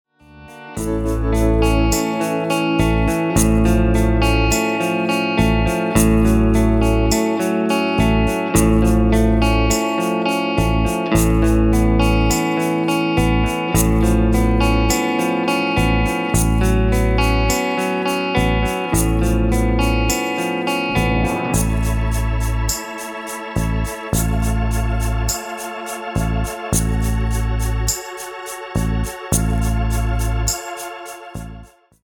A play-along track in the style of rock.